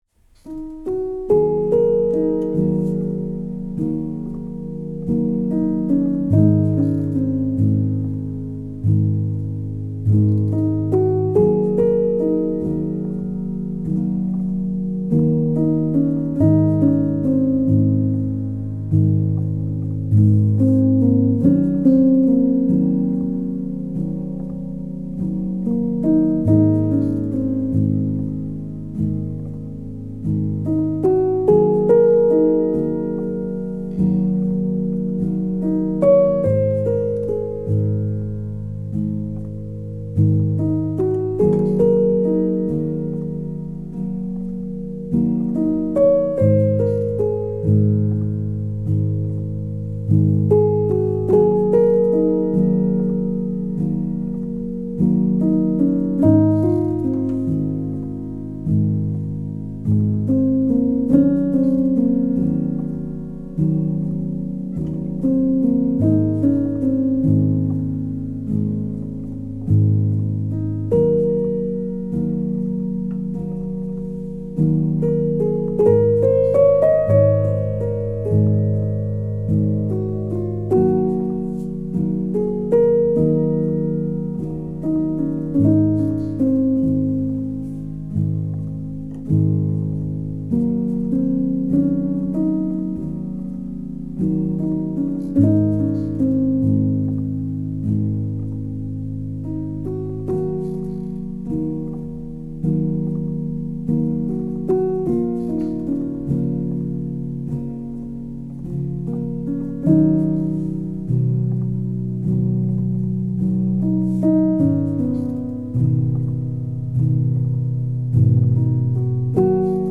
a modern classical solo piano single